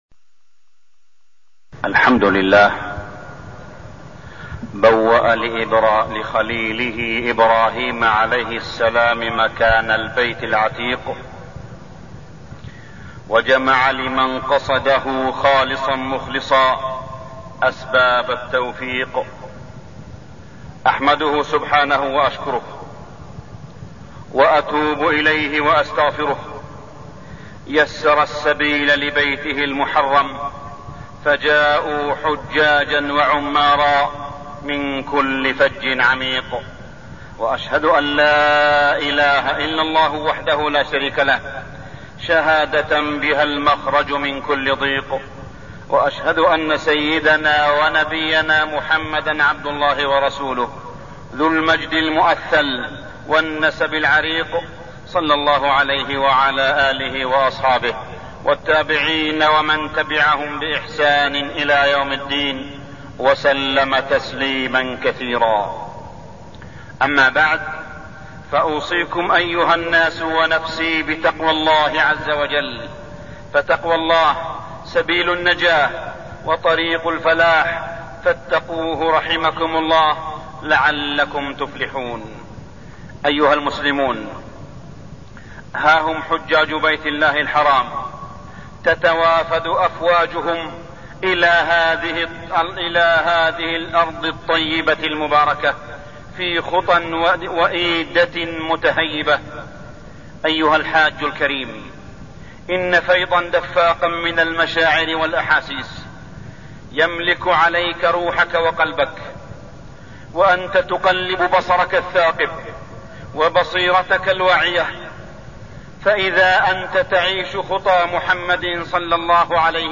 تاريخ النشر ٢٤ ذو القعدة ١٤١٦ هـ المكان: المسجد الحرام الشيخ: معالي الشيخ أ.د. صالح بن عبدالله بن حميد معالي الشيخ أ.د. صالح بن عبدالله بن حميد وفود الحجيج إلى مكة